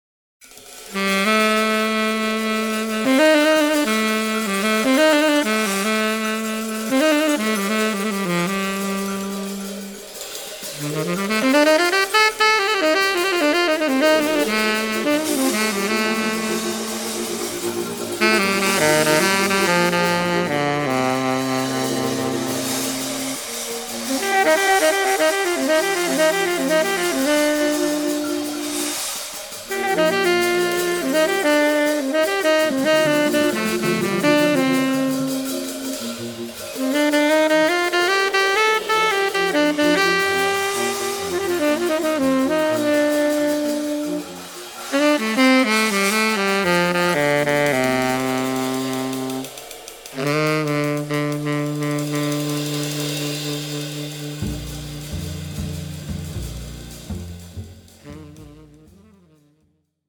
impressive post-bop offering
Genre: Jazz.
tenor saxophone
bass
drums